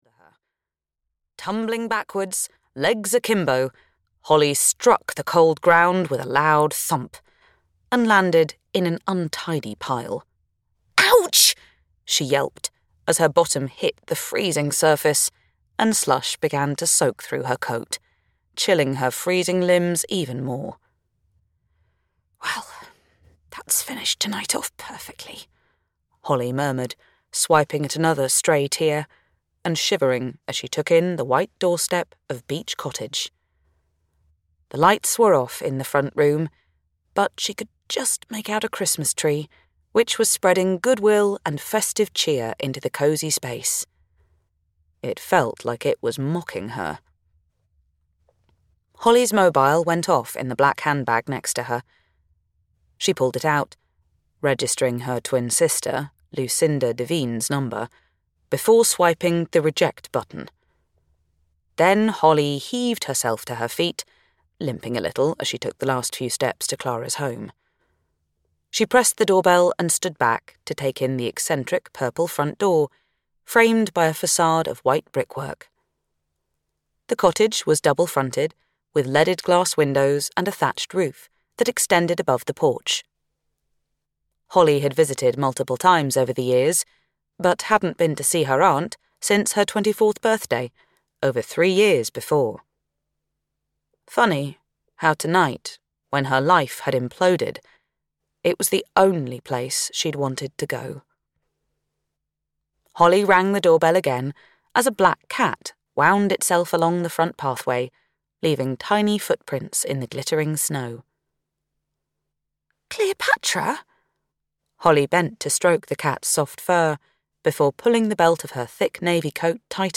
The Christmas Countdown (EN) audiokniha
Ukázka z knihy